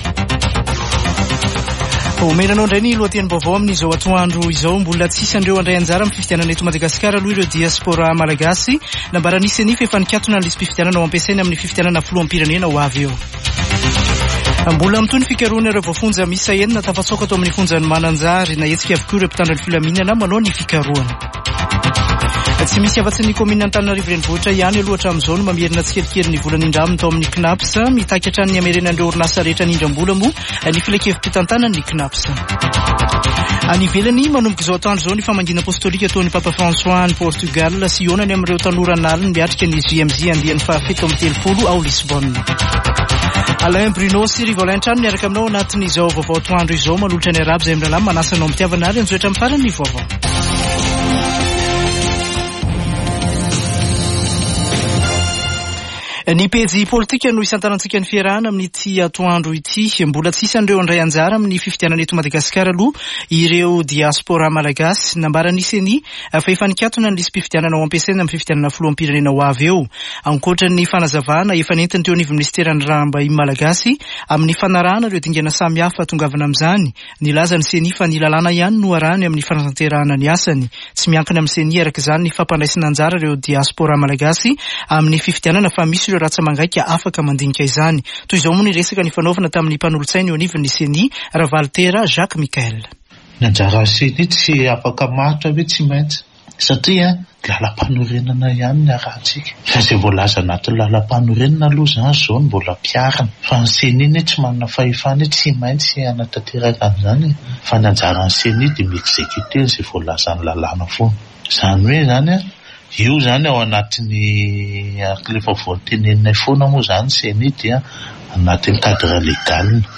[Vaovao antoandro] Alarobia 2 aogositra 2023